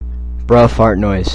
Sound Effects
Bruh Fart Noise